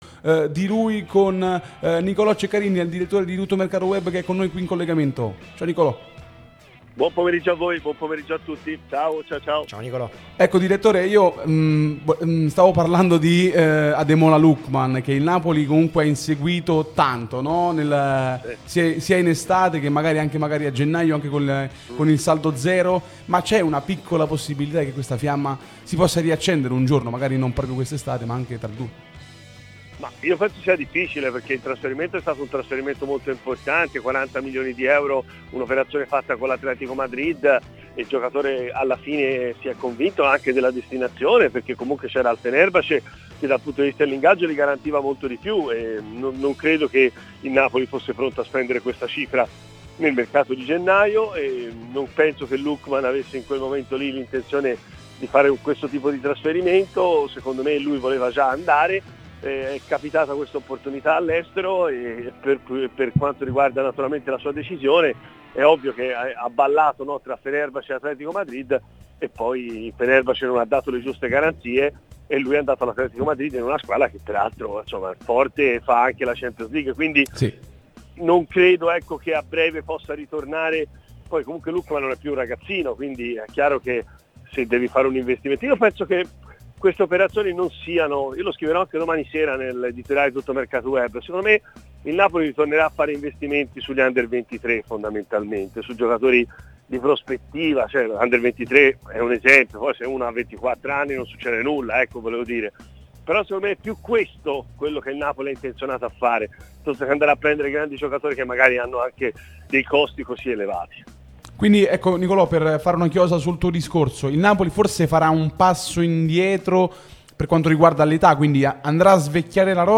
Radio Tutto Napoli
è intervenuto nel corso di "Napoli Talk" sulla nostra Radio Tutto Napoli